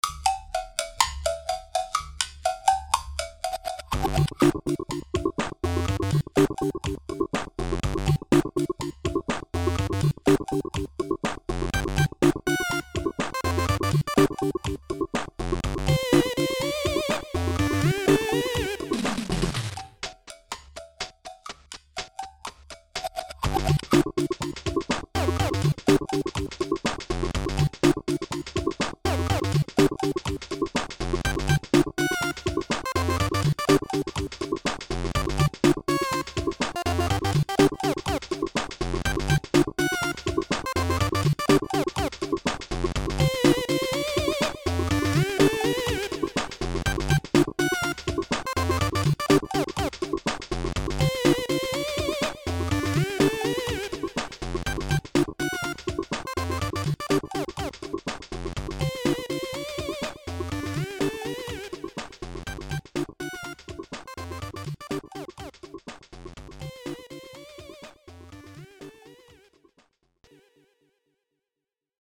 Let your hair down - Anything remotely chip and/or fakebit!